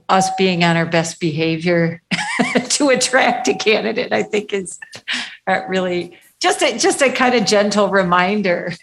Commissioner Fran Bruder Melgar says that also means courtesy is important:
fran-bruder-melgar.mp3